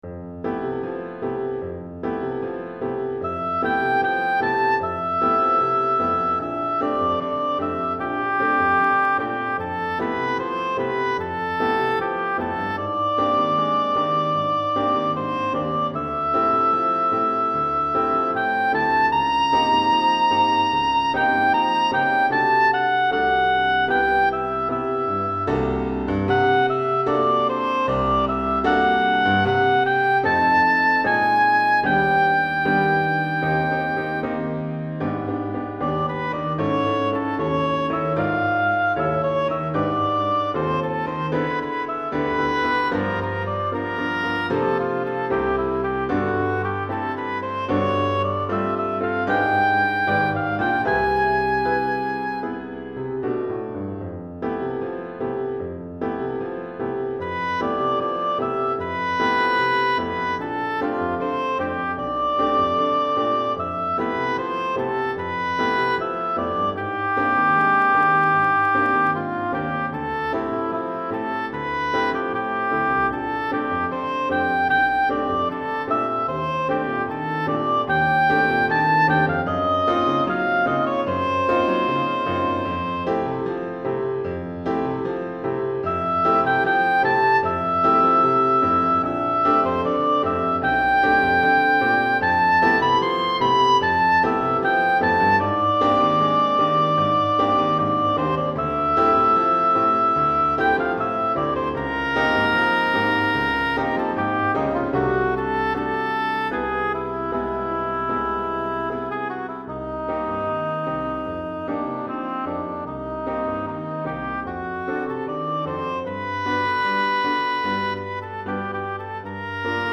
Hautbois et Piano